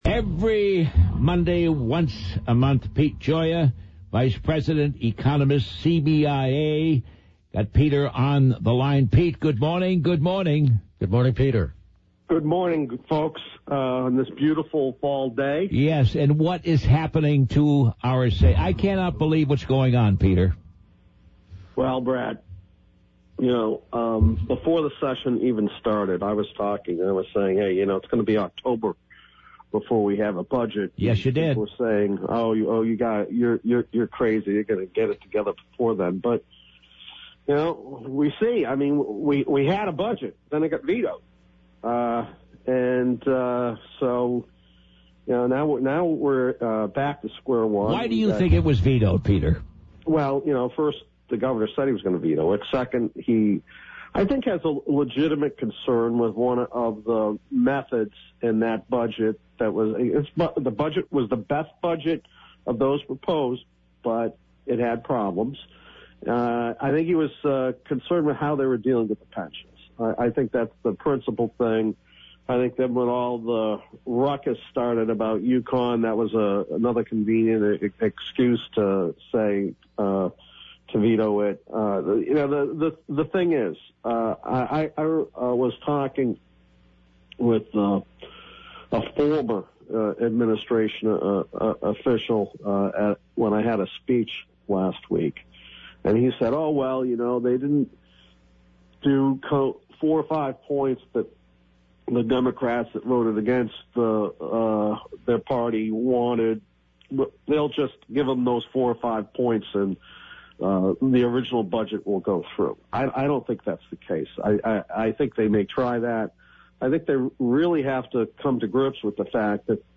explains in this interview.